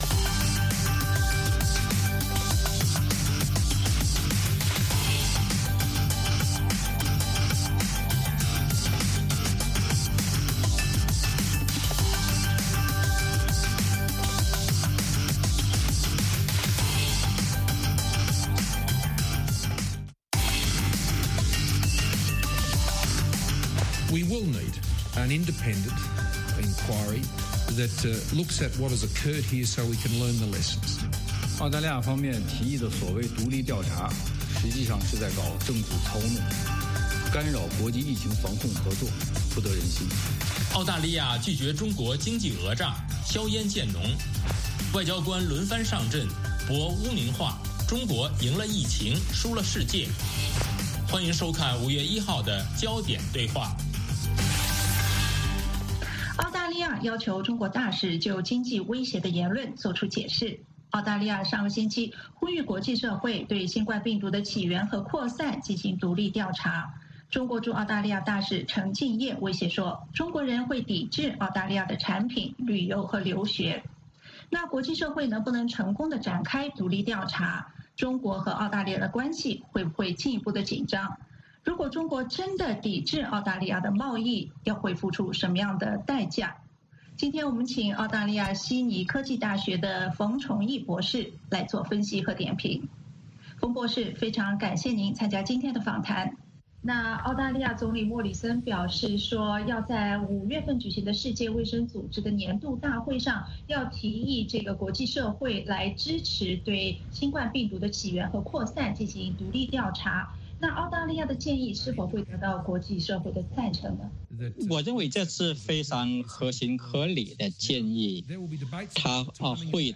美国之音中文广播于北京时间早上6－7点重播“焦点对话”节目。《焦点对话》节目追踪国际大事、聚焦时事热点。邀请多位嘉宾对新闻事件进行分析、解读和评论。